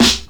• Old School Loud Rap Steel Snare Drum Sound A# Key 30.wav
Royality free acoustic snare tuned to the A# note. Loudest frequency: 2938Hz
old-school-loud-rap-steel-snare-drum-sound-a-sharp-key-30-RTu.wav